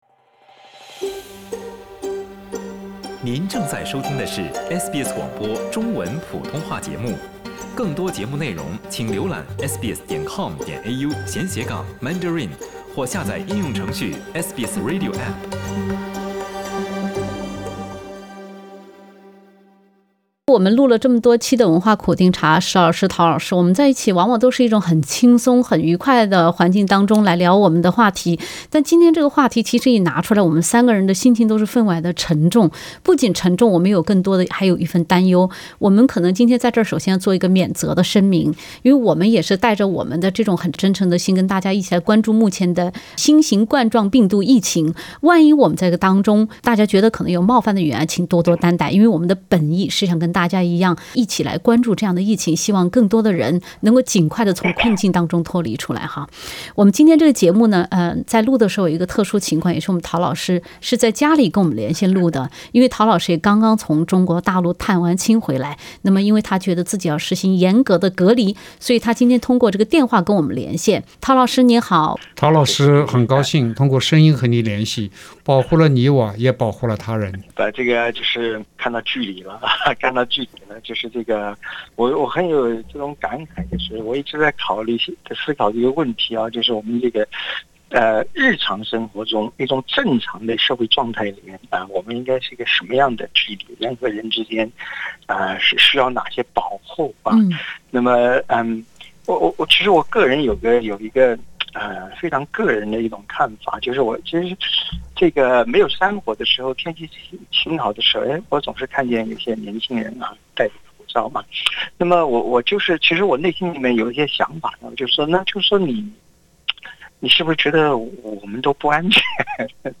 欢迎收听SBS 文化时评栏目《文化苦丁茶》，本期话题是：从文明的角度，有些文化需要改变。